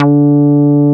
P MOOG D4MF.wav